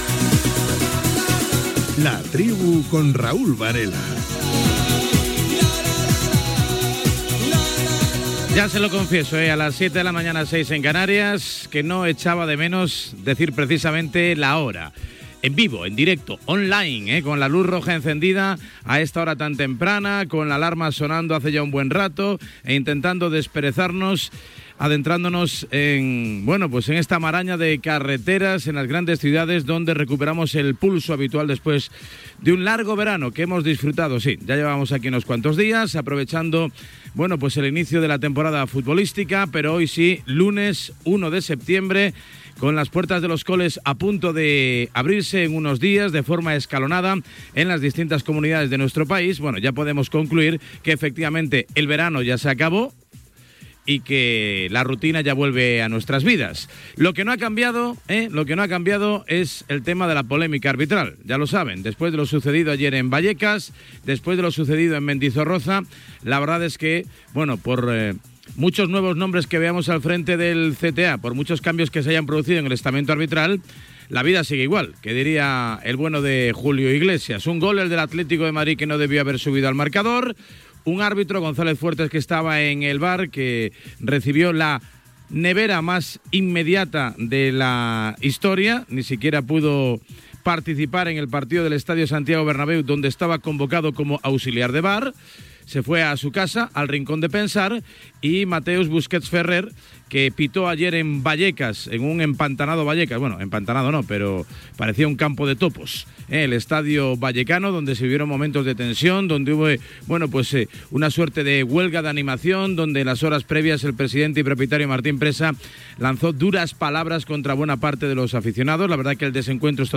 Careta, hora, data, inici del programa.
Esportiu
FM